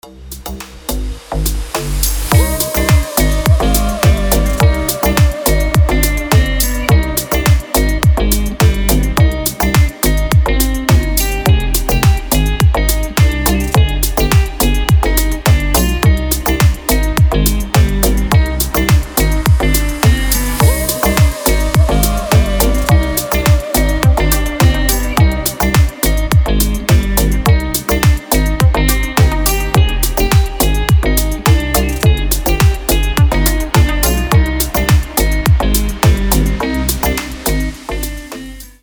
• Качество: 320, Stereo
deep house
атмосферные
без слов
Красивая мелодия с осенним настроением